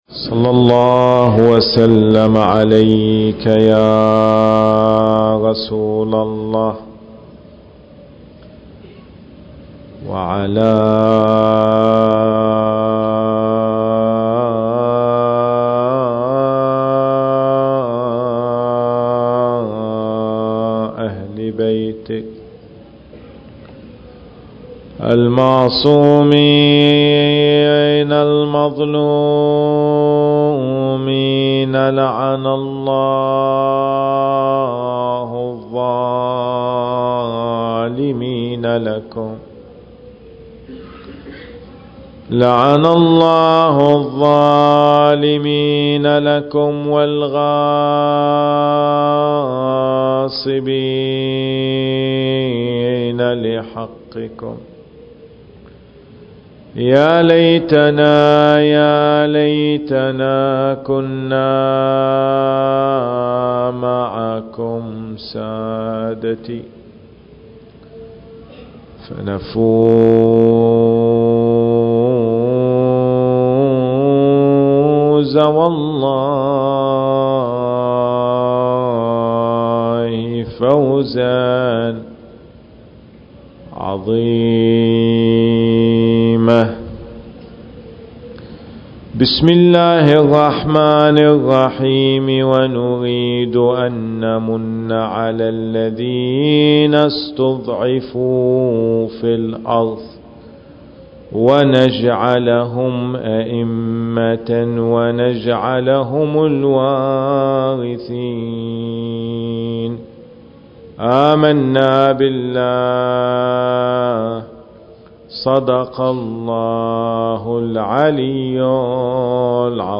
المكان: مأتم الزهراء (عليها السلام) - صفوى التاريخ: 10 محرم الحرام/ 1440 للهجرة